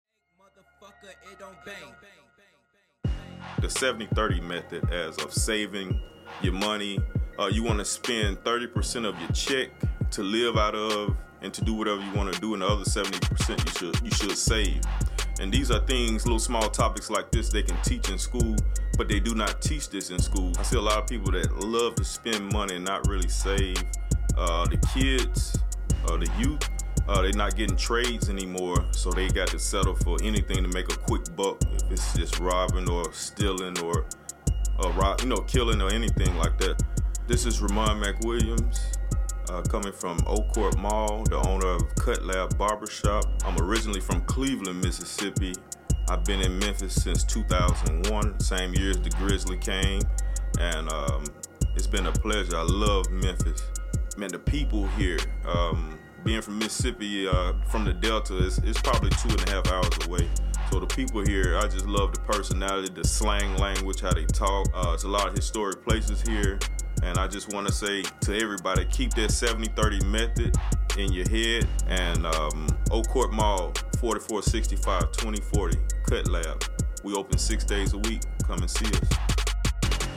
Rap(Music), Hip-hop, Popular music—Tennessee—Memphis, African Americans—Music, Memphis(Tenn.)—Social life and customs